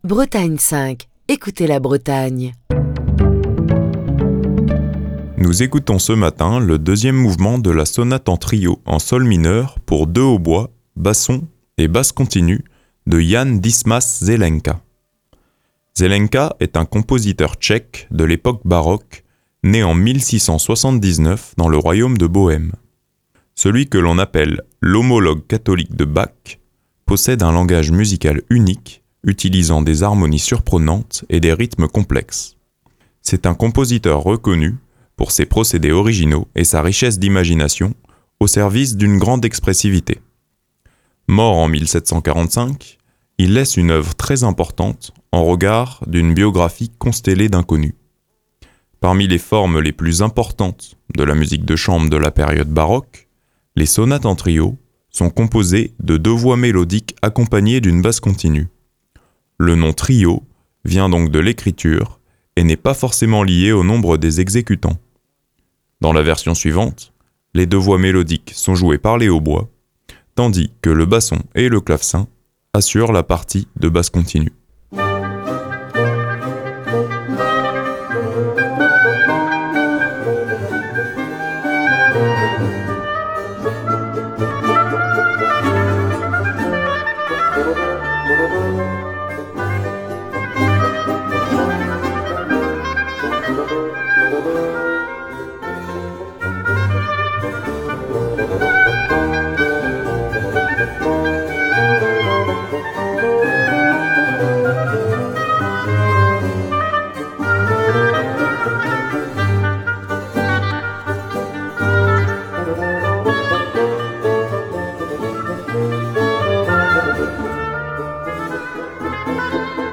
Nous écoutons ce matin le 2ème mouvement de la sonate en trio en sol mineur pour 2 hautbois, basson et basse continue de Jan Dismas Zelenka.
Parmi les formes les plus importantes de la musique de chambre de la période baroque, les sonates en trio sont composées de deux voix mélodiques accompagnées d'une basse continue.
Dans la version suivante, les deux voix mélodiques sont jouées par les hautbois, tandis que le basson et le clavecin assurent la partie de basse continue.
Preuve si il en fallait que l’on peut interpréter cette musique avec des instruments modernes, tout en respectant le style de l’époque.